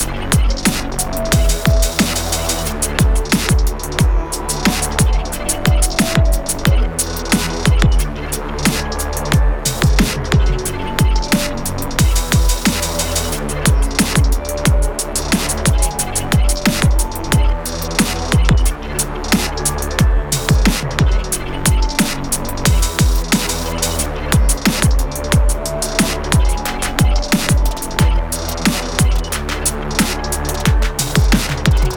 Music - Song Key
Gb Minor